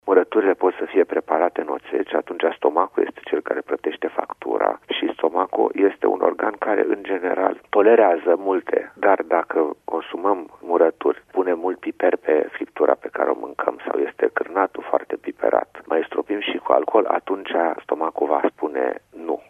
stiri-25-dec-doctor-condimente.mp3